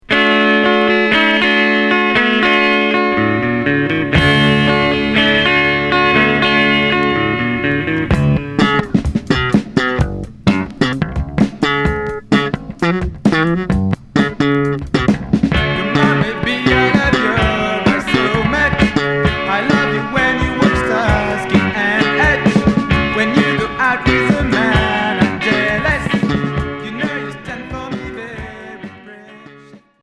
Groove rock Unique 45t retour à l'accueil